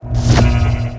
metabolize_fire_reverse.wav